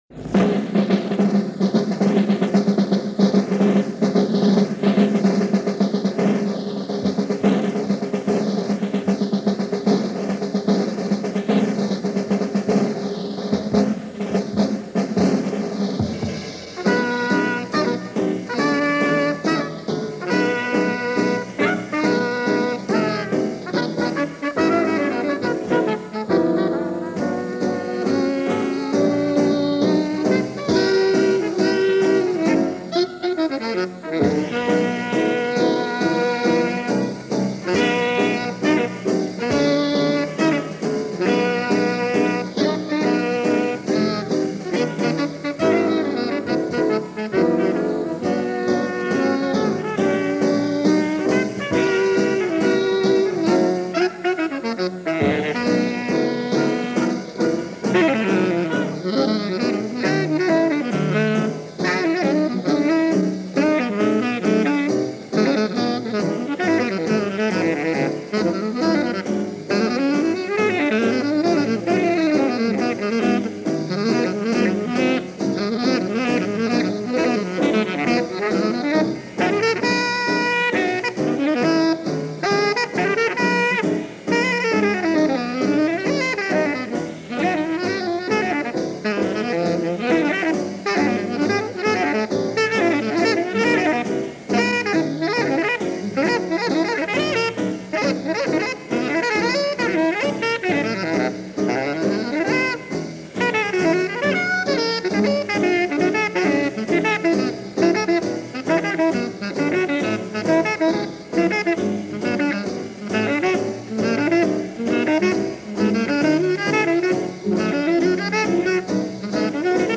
Trumpet
sax
piano
bass